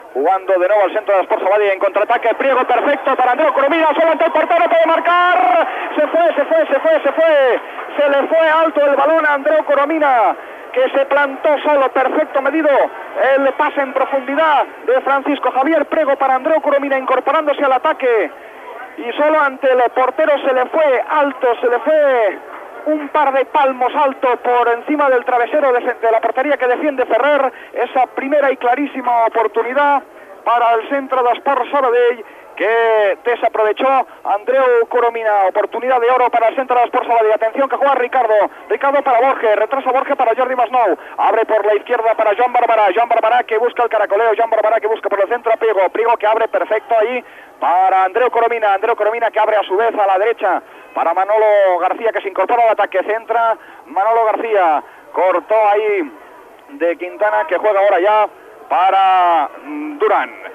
Fragment de la narració del partit de futbol masculí C.E. Sabadell - Figueres
Esportiu